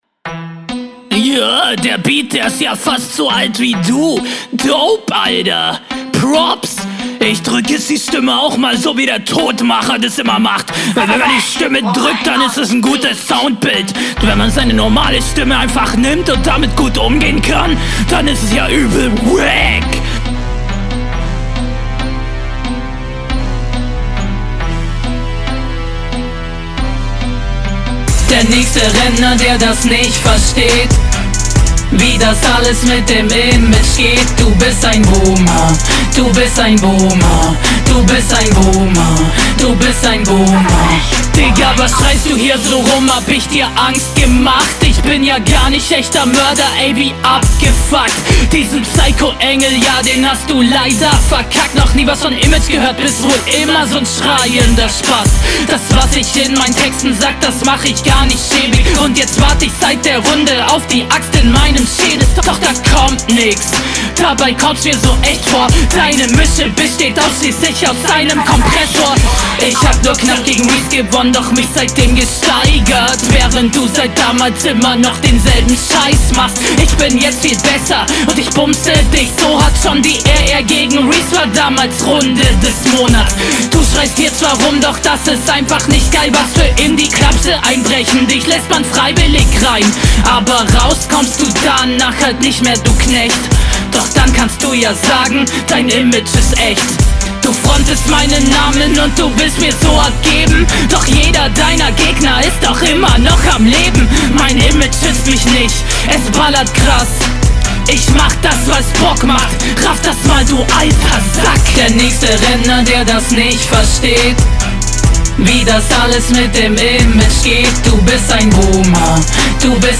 Flow: Hook absolut geil.
Haha das Intro ist wundervoll xD Insgesamt bist du musikalischer, die Hook ist super.